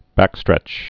(băkstrĕch)